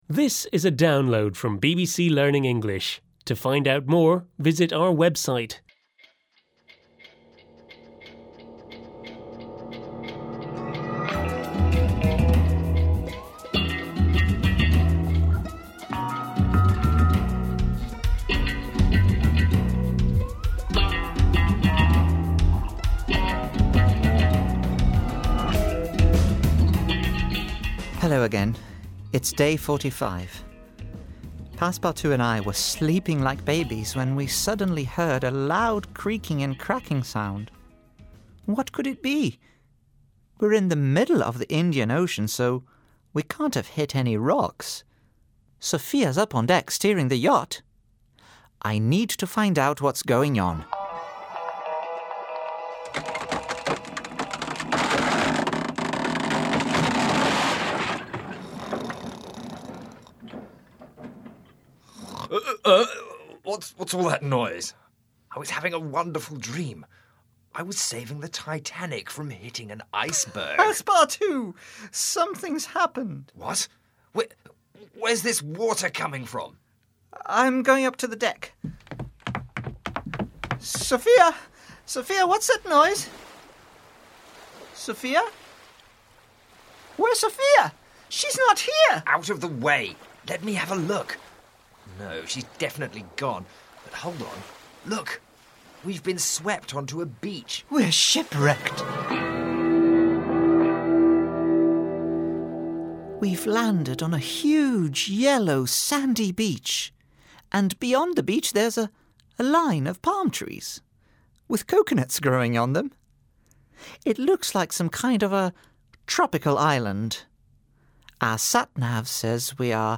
unit-7-5-1-u7_eltdrama_therace_download.mp3